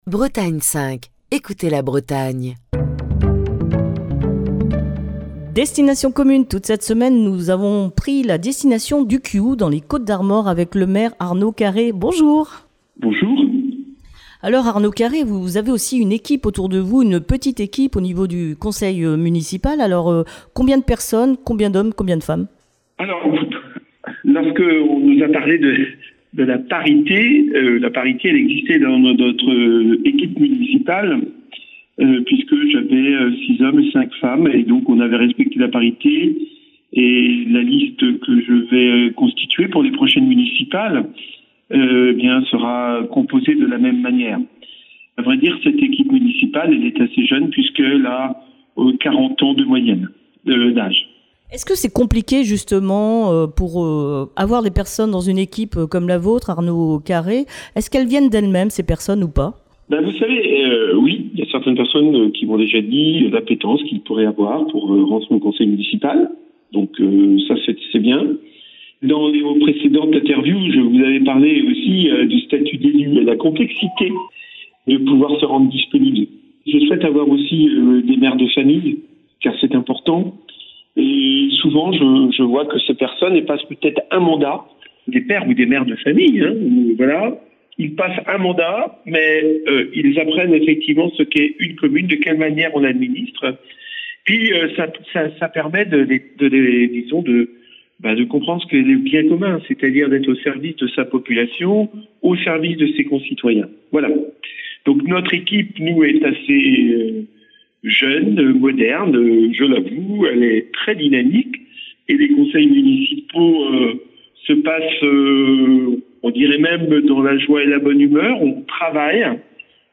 maire du Quiou dans les Côtes-d'Armor, où Destination Commune a posé ses micros cette semaine.